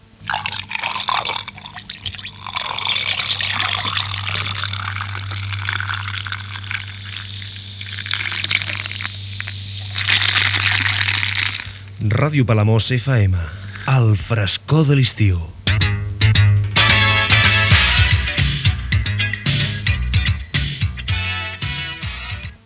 Identificació d'estiu